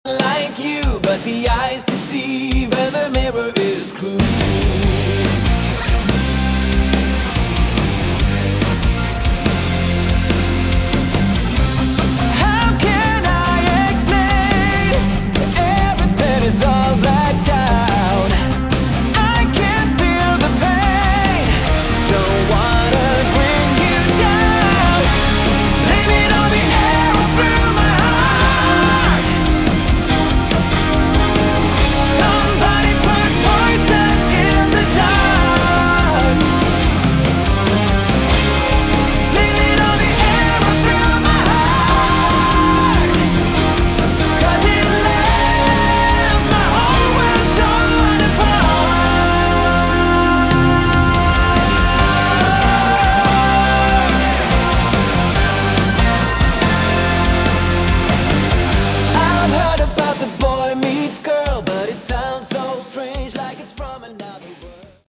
Category: Melodic Rock
lead & backing vocals
guitars
piano & keyboards
bass
drums
Uplifting and extremely melodic as you'd expect.